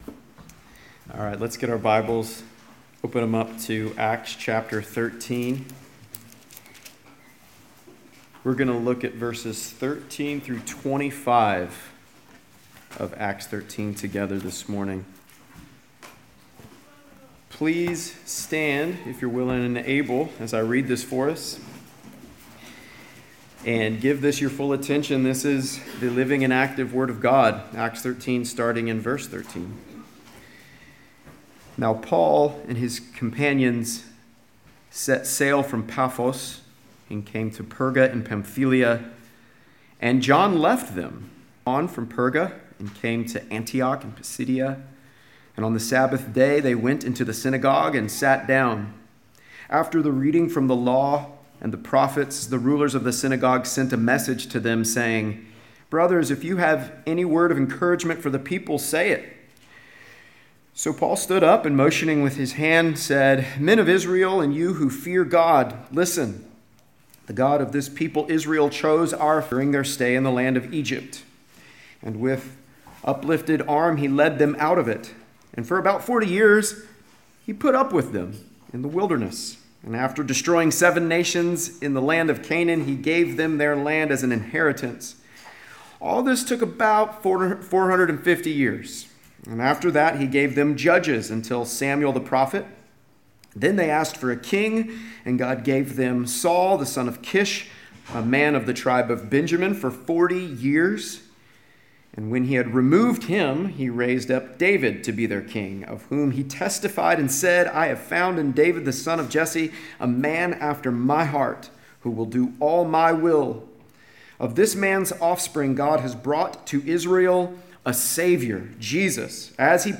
Sunday Worship
Sermon